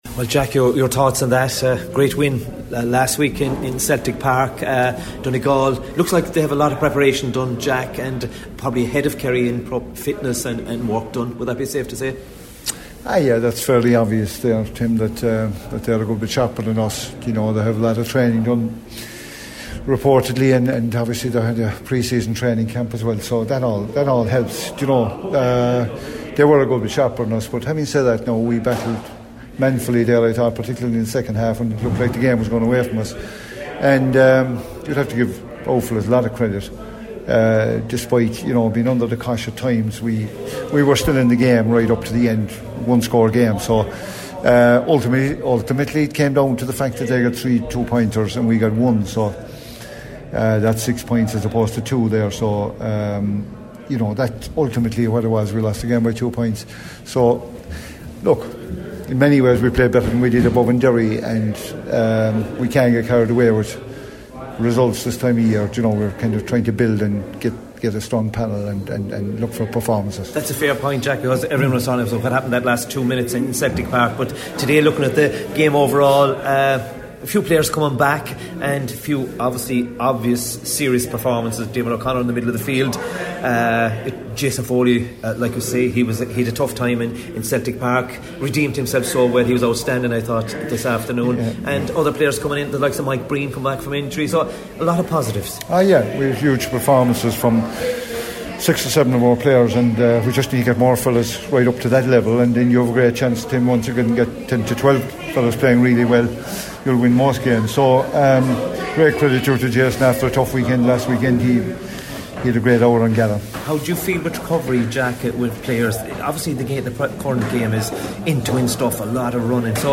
After the game, O’Connor gave his thoughts